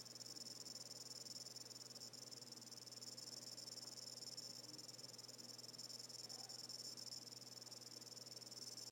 Noch eine Frage zu der Cam: Machen eure Kameras Geräusche? Immer wenn ich den Stream aktiviere, hört es sich an, als hätte ich mehrere Grillen im Zimmer. esp32_cam.m4a Vielen Dank